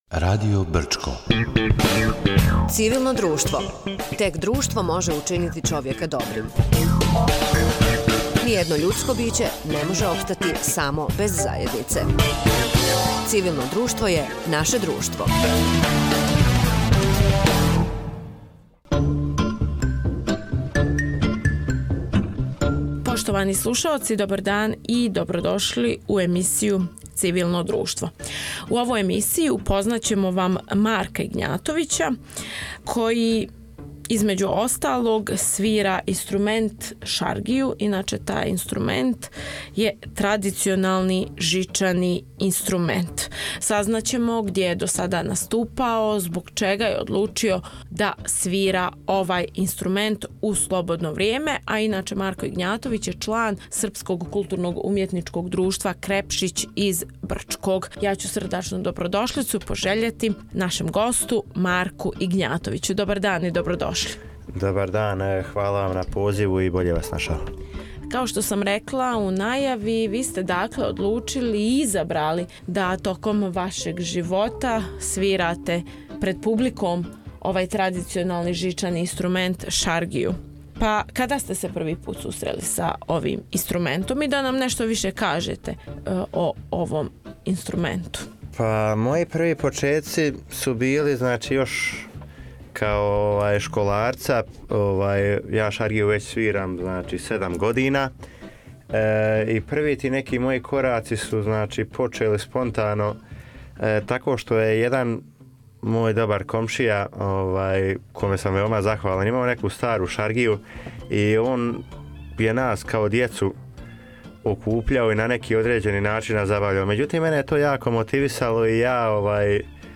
Када се први пут заинтересовао за овај јединствени инструмент, шта га је привукло и како је текло његово музичко путовање – сазнајте у инспиративном разговору који слави културно насљеђе.